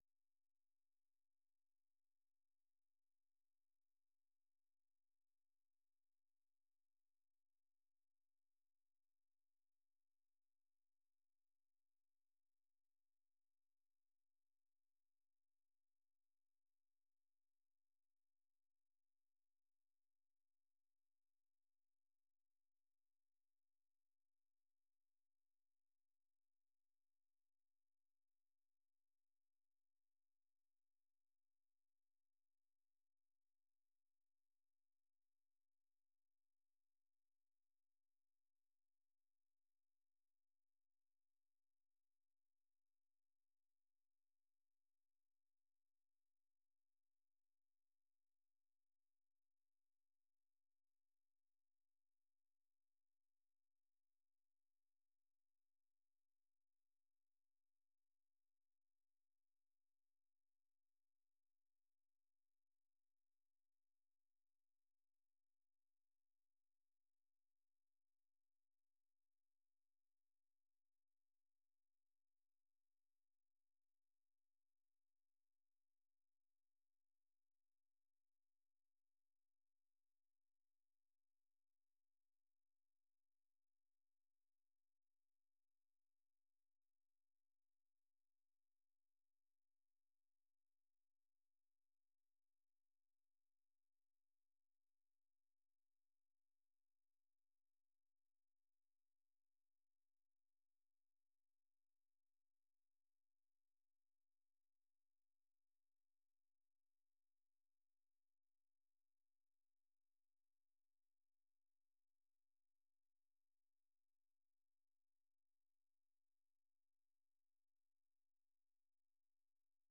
The URL has been copied to your clipboard 페이스북으로 공유하기 트위터로 공유하기 No media source currently available 0:00 0:59:58 0:00 생방송 여기는 워싱턴입니다 생방송 여기는 워싱턴입니다 공유 생방송 여기는 워싱턴입니다 share 세계 뉴스와 함께 미국의 모든 것을 소개하는 '생방송 여기는 워싱턴입니다', 아침 방송입니다.